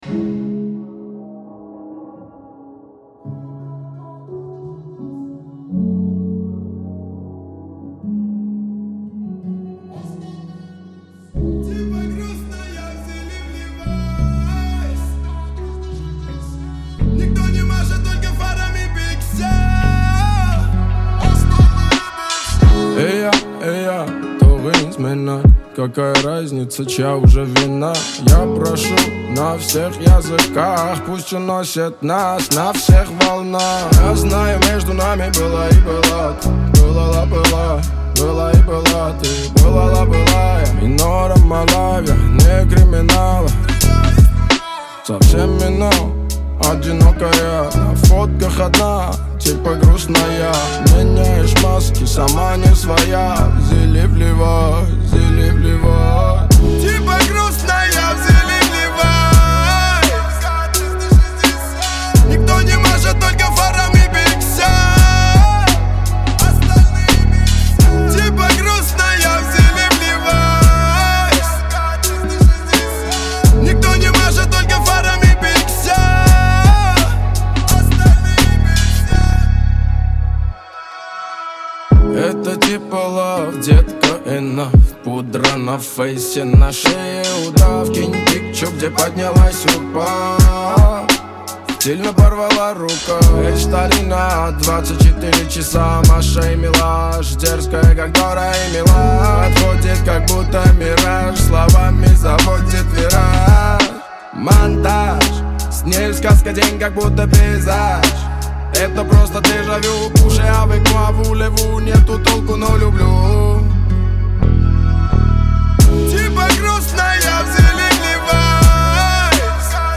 Жанр - поп